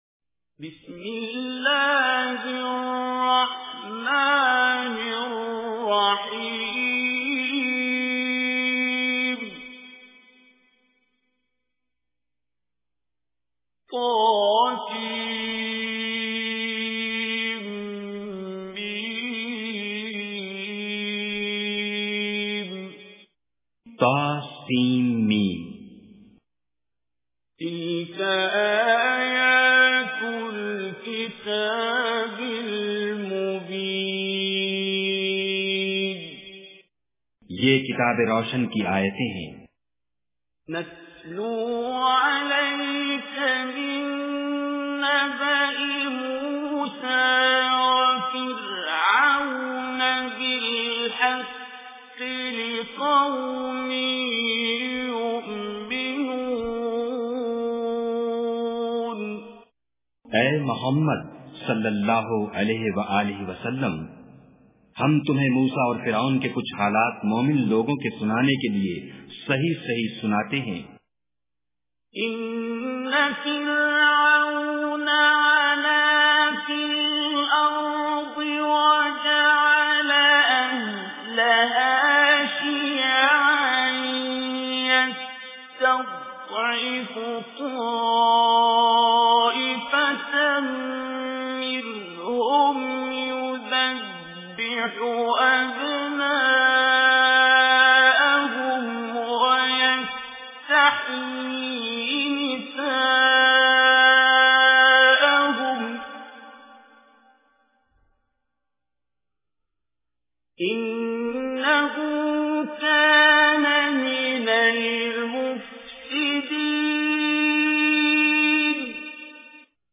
Surah Al-Qasas Recitation with Urdu Translation
Listen online and download beautiful Quran Recitation / Tilawat of Surah Al Qasas in the voice of Qari Abdul Basit As Samad.